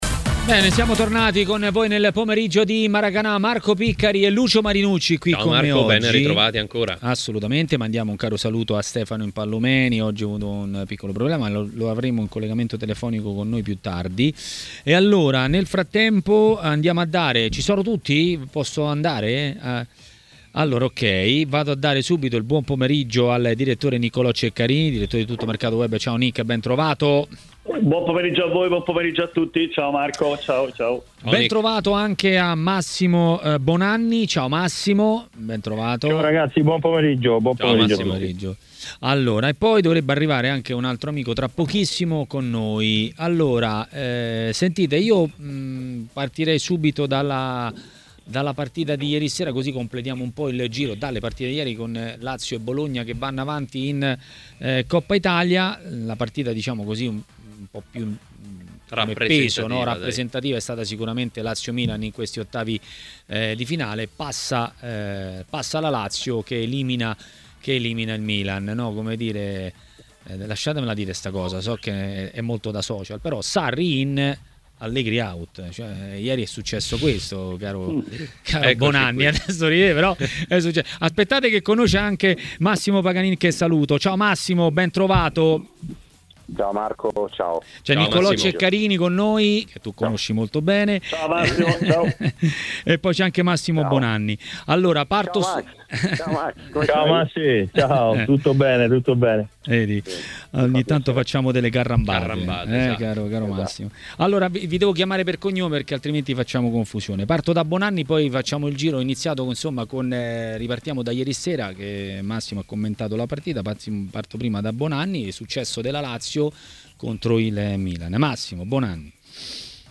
L'ex calciatore Massimo Paganin ha parlato dei temi del giorno a TMW Radio, durante Maracanà.